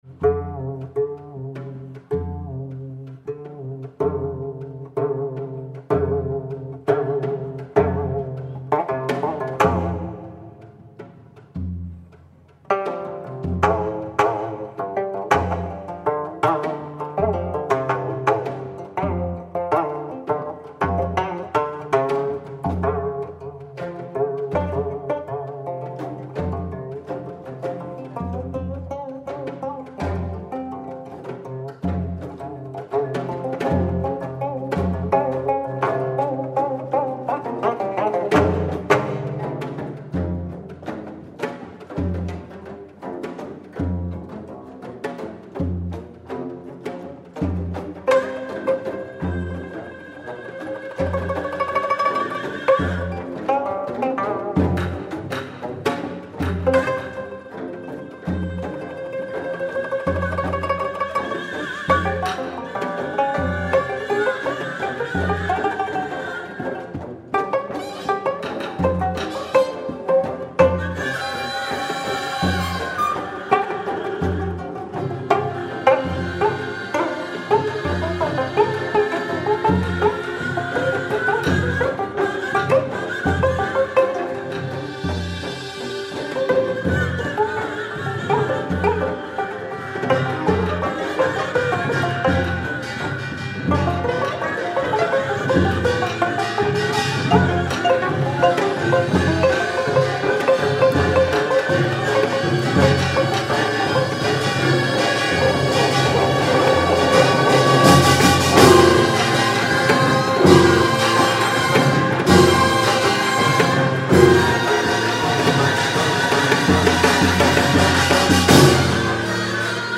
gayageum concerto